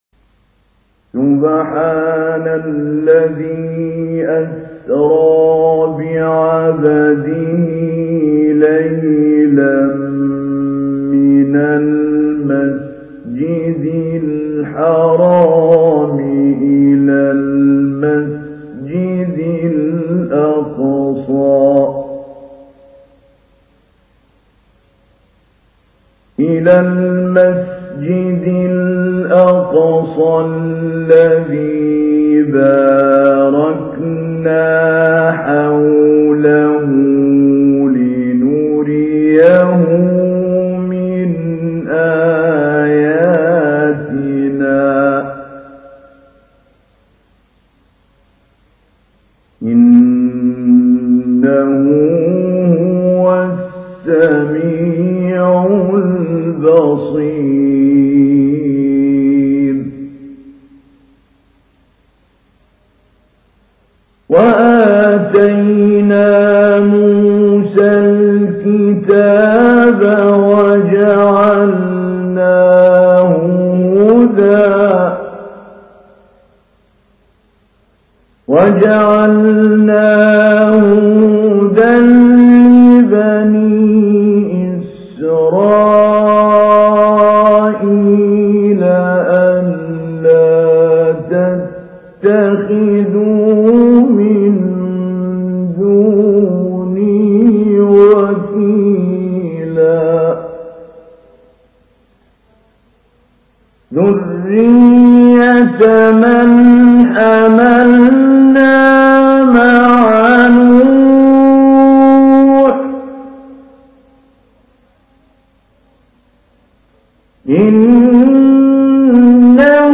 تحميل : 17. سورة الإسراء / القارئ محمود علي البنا / القرآن الكريم / موقع يا حسين